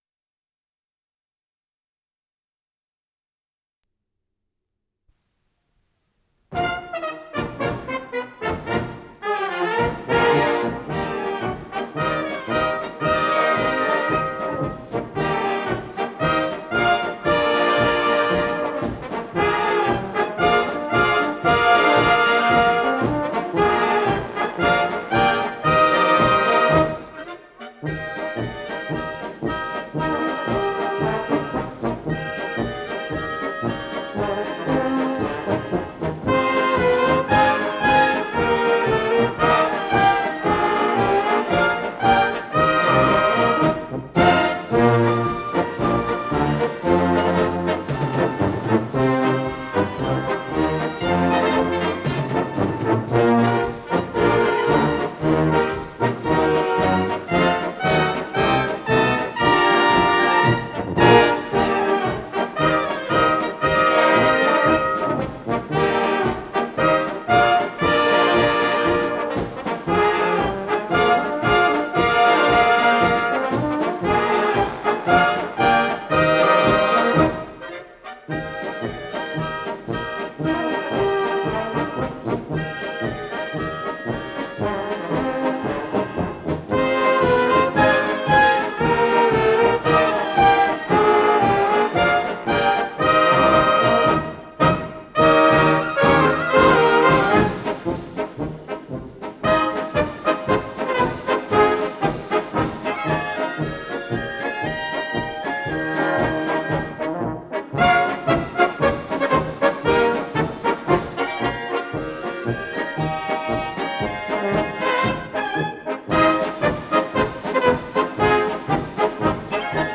с профессиональной магнитной ленты
ПодзаголовокПолька